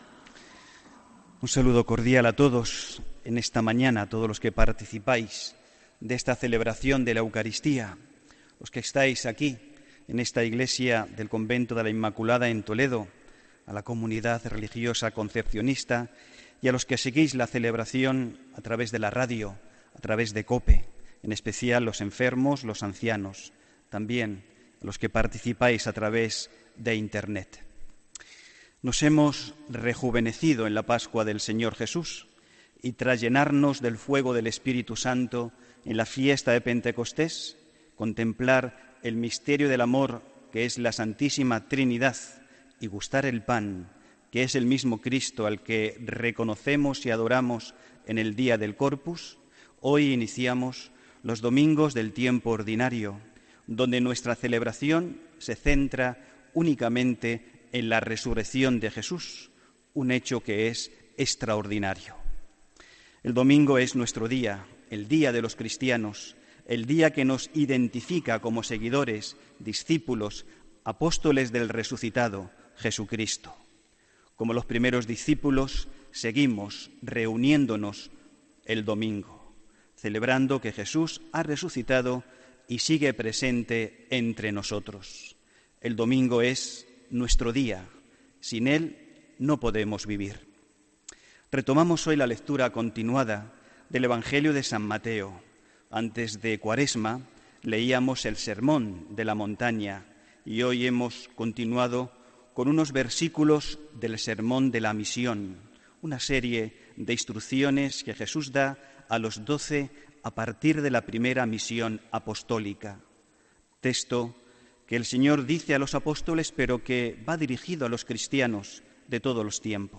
Homilía del domingo 25 de junio de 2017